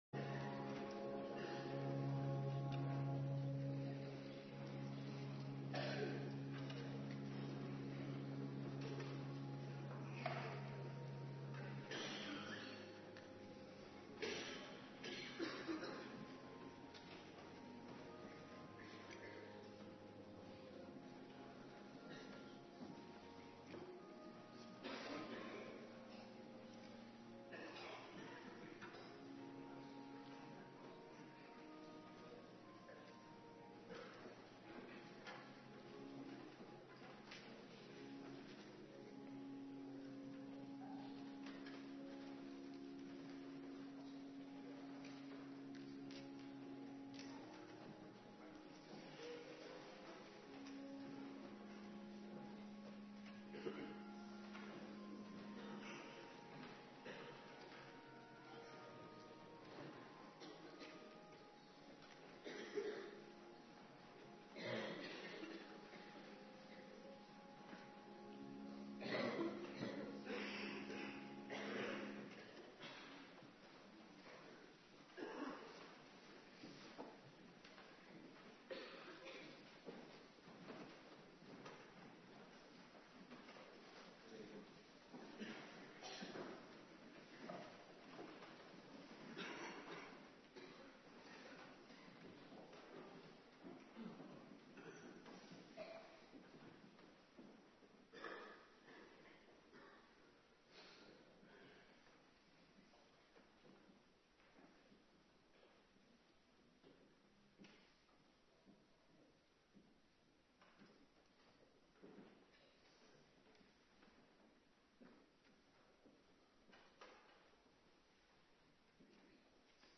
Morgendienst
09:30 t/m 11:00 Locatie: Hervormde Gemeente Waarder Agenda